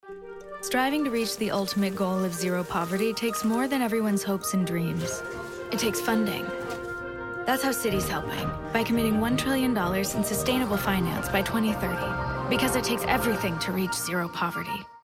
Narration Demo